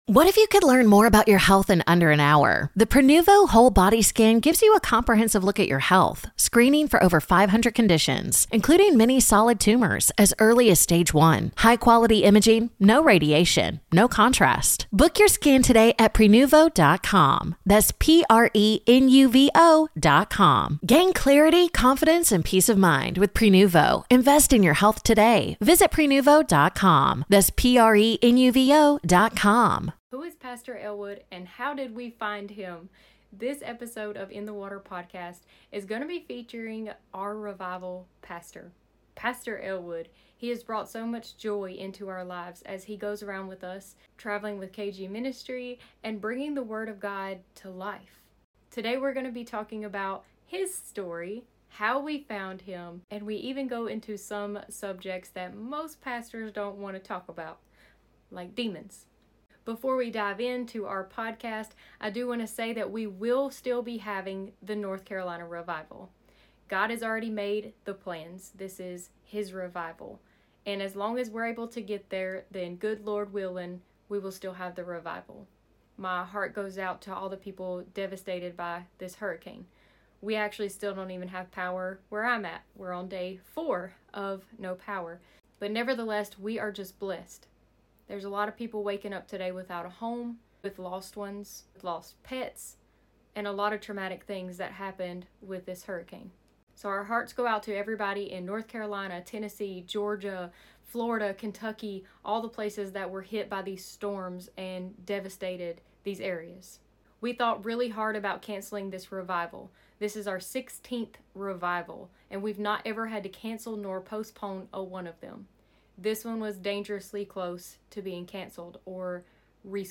Don’t miss this deep, faith-filled conversation about ministry, the spiritual realm, and the work of God in our lives.